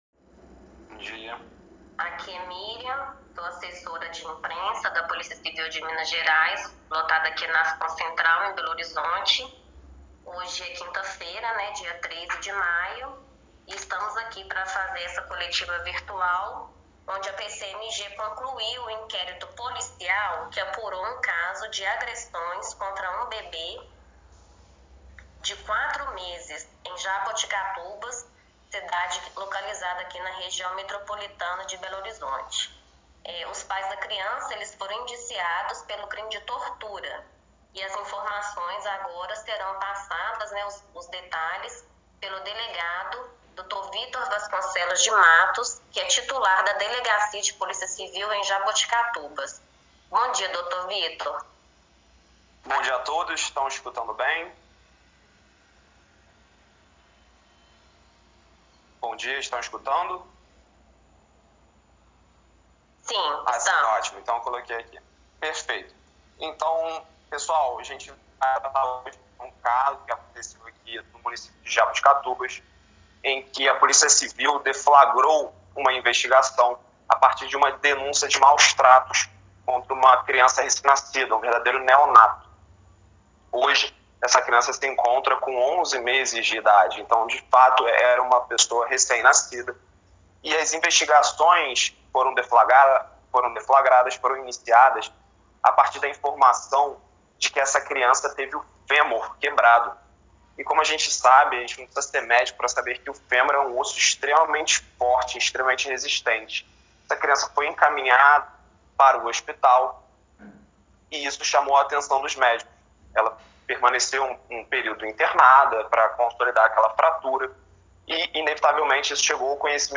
Sonora da coletiva